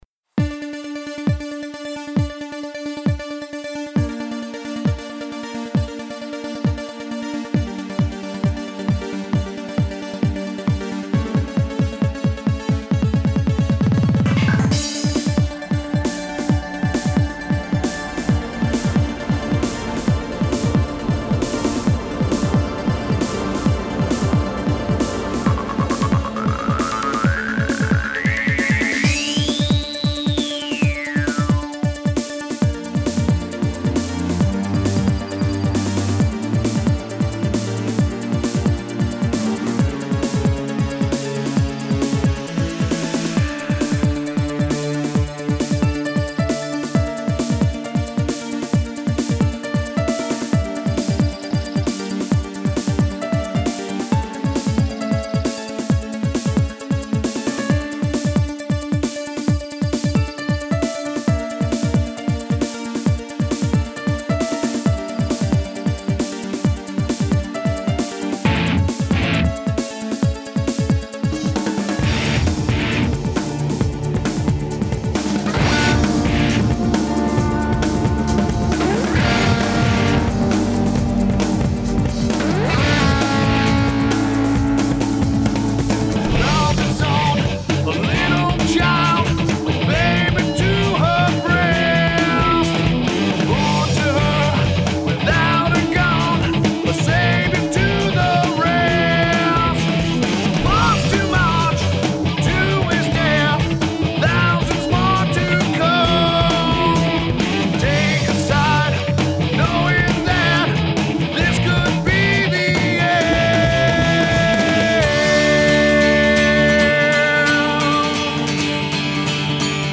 Guitar/Vocals
Lead Vocals
Drums
Bass
Keys